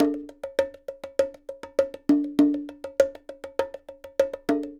Bongo 12.wav